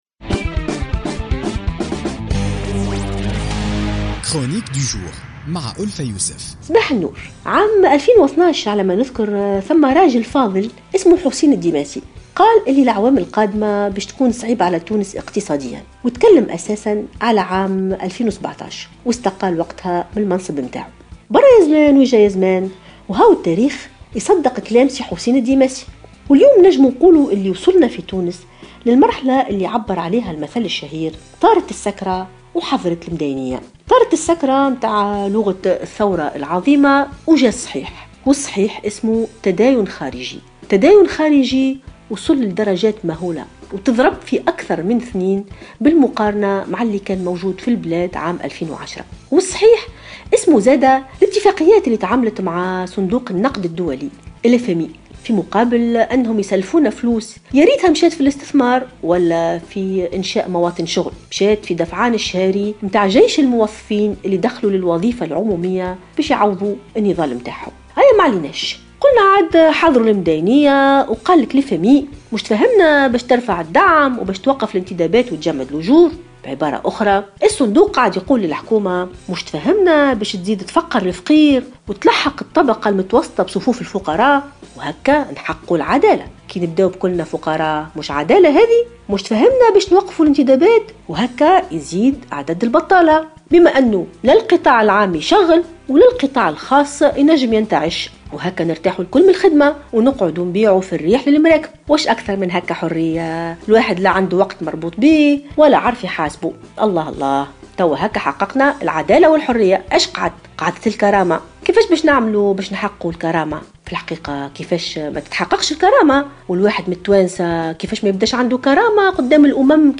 تطرقت الكاتبة ألفة يوسف في افتتاحية اليوم الثلاثاء 18 أكتوبر 2016 إلى المرحلة التي وصلت لها تونس مشيرة إلى أنها وصلت لمرحلة مواجهة الحقيقة التي تتمثل في أنها قد أغرقت بالديون الخارجية .